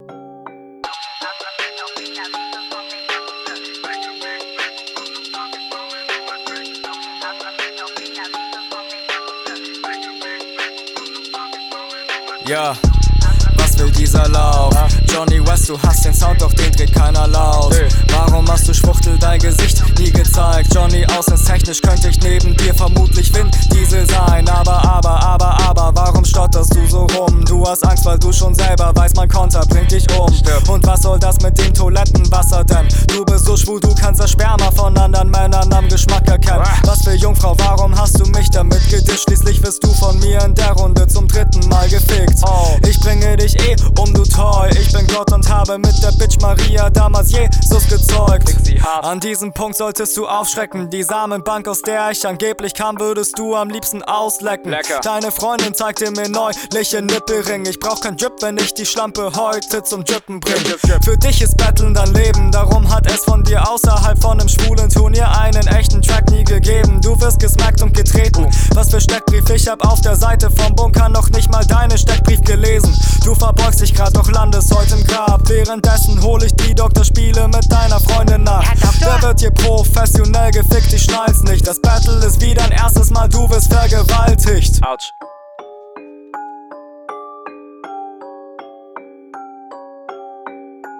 kommst nicht so cool auf dem Beat. wieder hab ich das Gefühl, dass du hier …